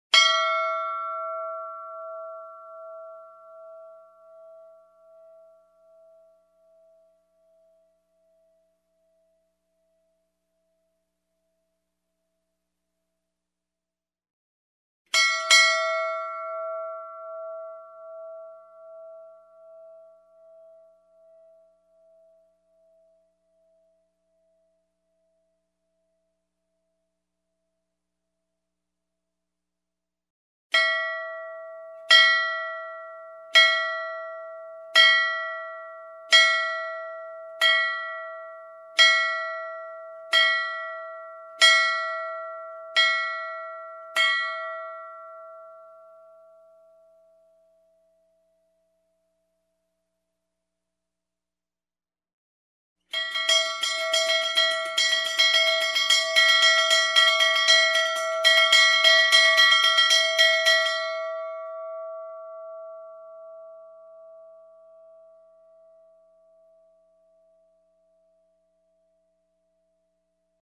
Звуки звона
Морской корабельный колокол